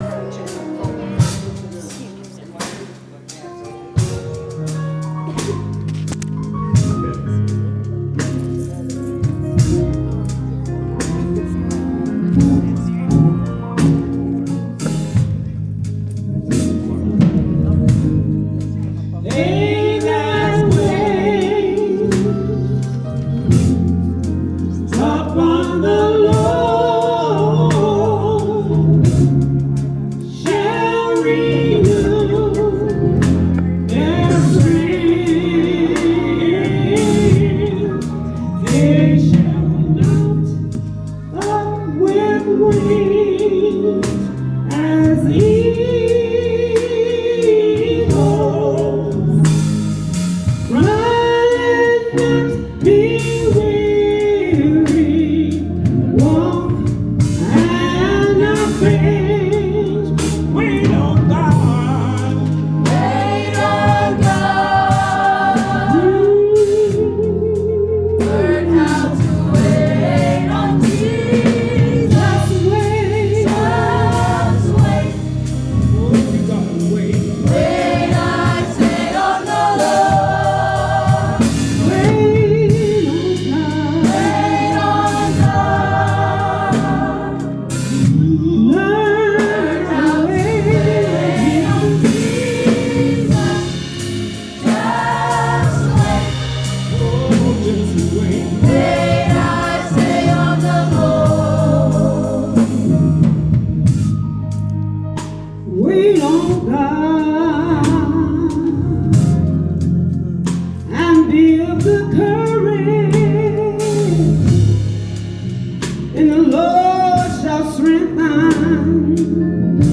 Choir Gospel